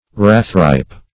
Search Result for " rathripe" : The Collaborative International Dictionary of English v.0.48: Rathripe \Rath"ripe`\ (r[a^]th"r[imac]p`), a. Rareripe, or early ripe.
rathripe.mp3